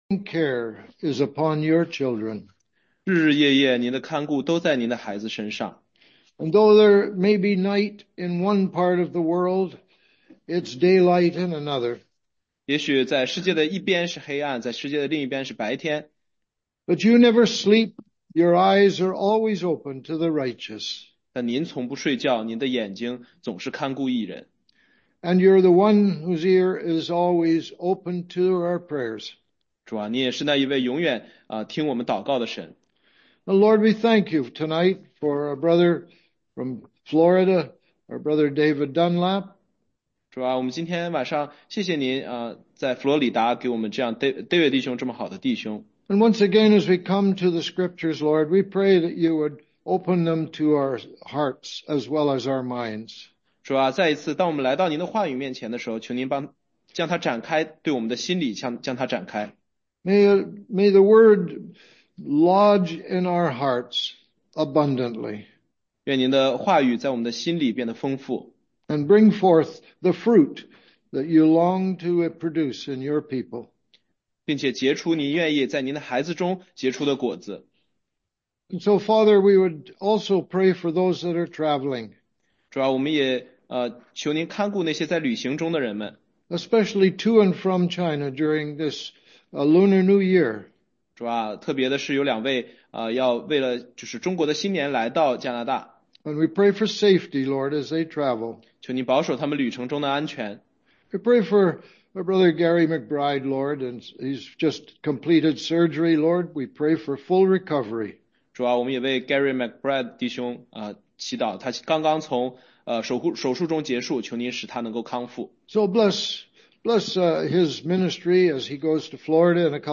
中英文查经